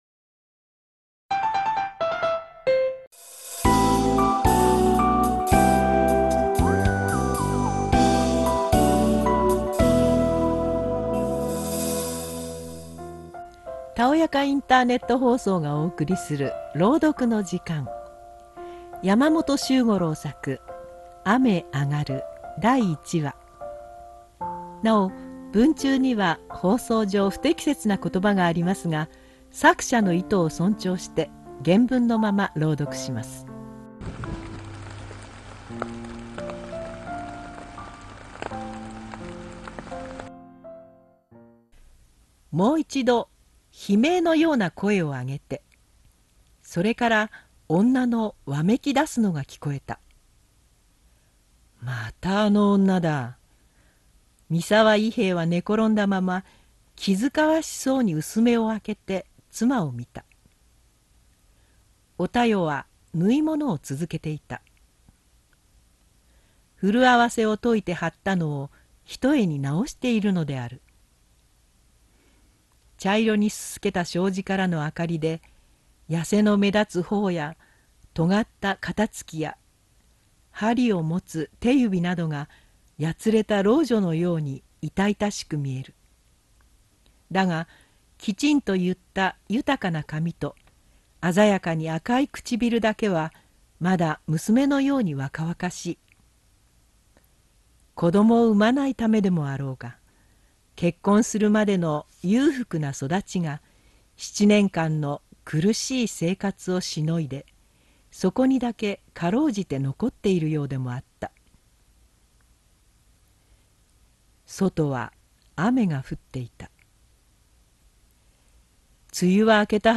たおやかインターネット放送 - （たおやかインターネット放送)朗読の時間雨あがる第１話